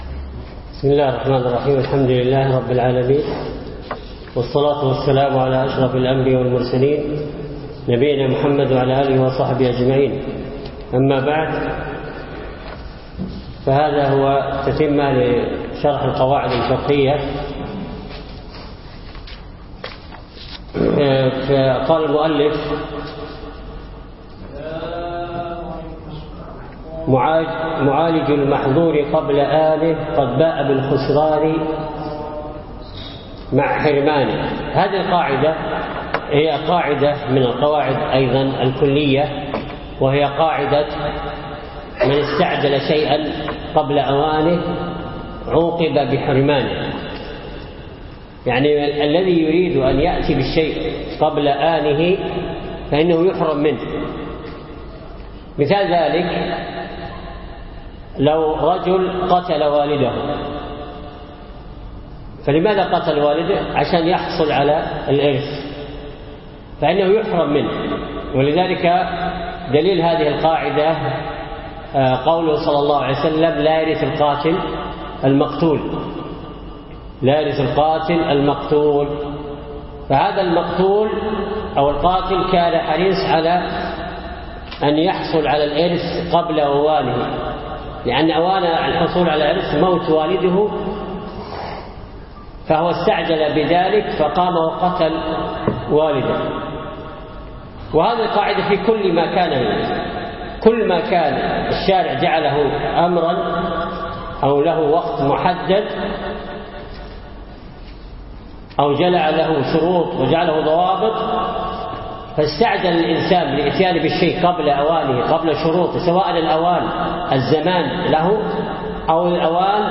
أقيم الدرس بعد مغرب السبت 7 3 2015 في مسجد أبي بكرة الثقفي منطقة العارضية